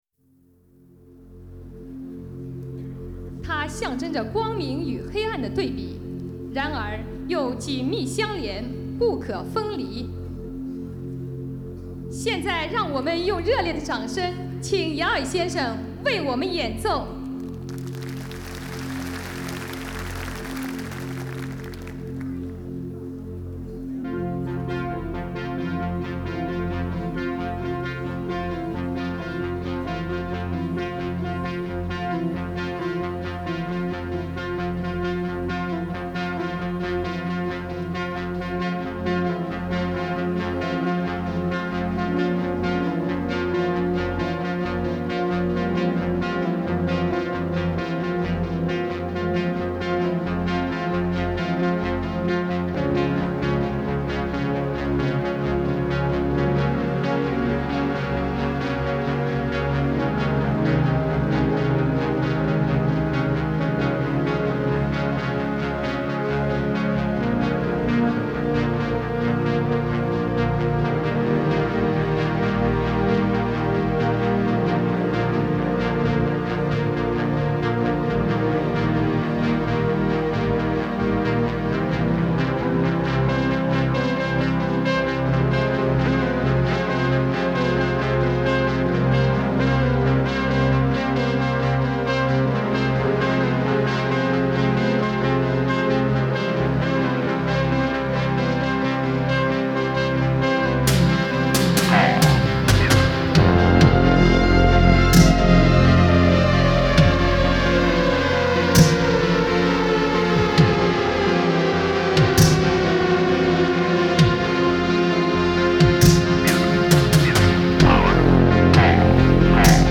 Genre : Techno